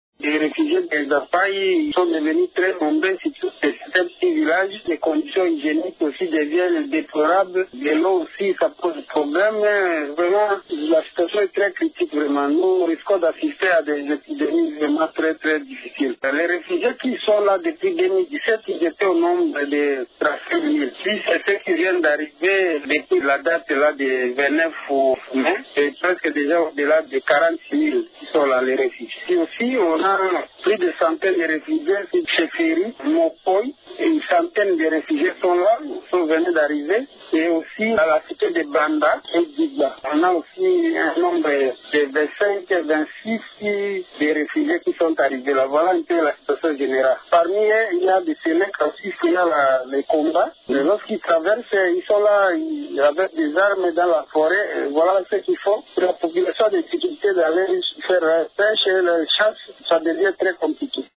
L’administrateur du territoire d’Ango, Marcellin Mazale Lekabusiya, explique :